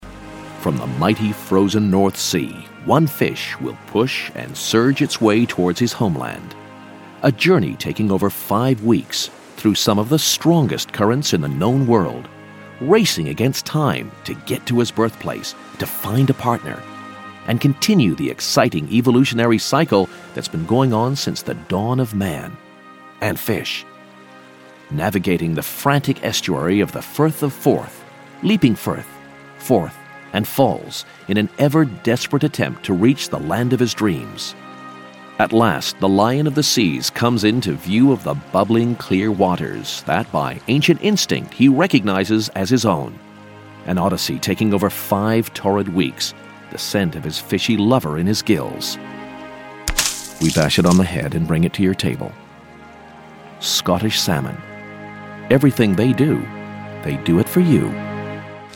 Male, 40s, 50s, 60s, American, DJ, The Kid, commercial, advert, voiceover, voice over, DGV, Damn Good Voices, damngoodvoices, Crying Out Loud, cryingoutloud,